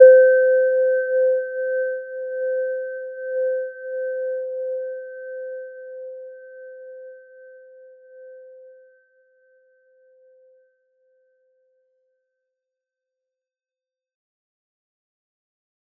Gentle-Metallic-1-C5-p.wav